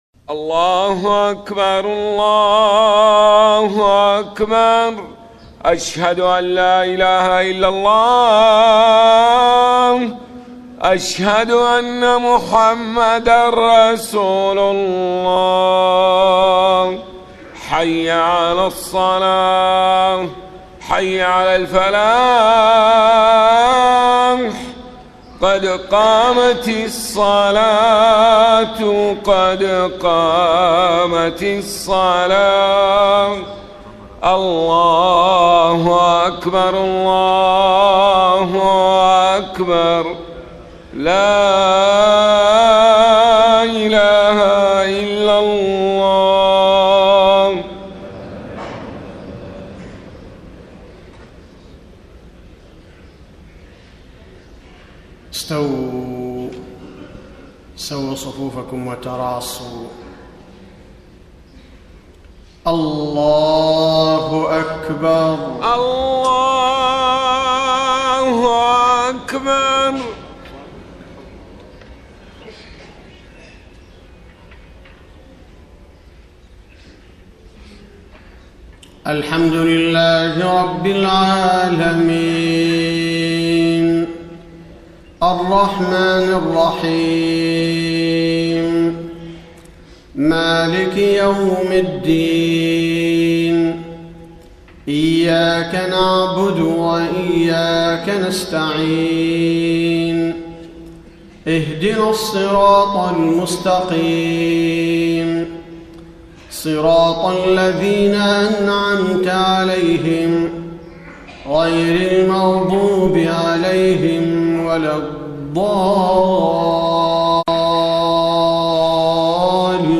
صلاة الفجر 4-4-1434هـ من سورة البقرة 254-263 > 1434 🕌 > الفروض - تلاوات الحرمين